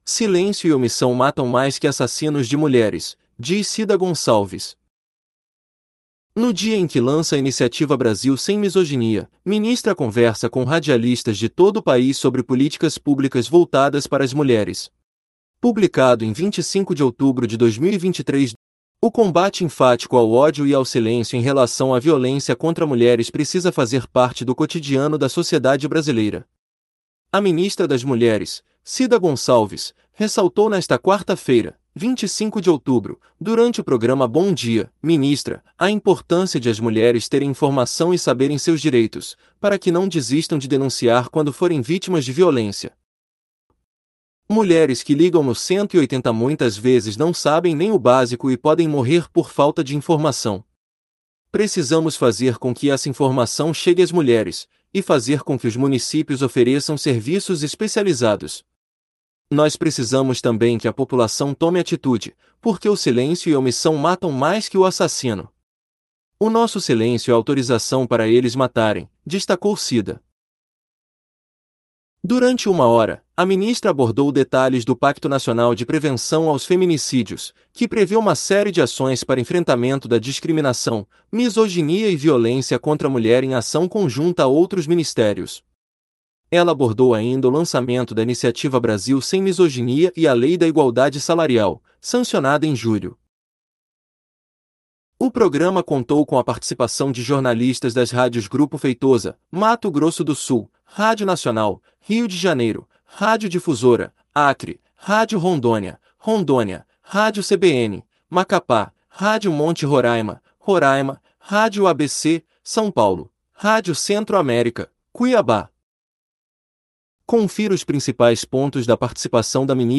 No dia em que lança a iniciativa Brasil sem Misoginia, ministra conversa com radialistas de todo país sobre políticas públicas voltadas para as mulheres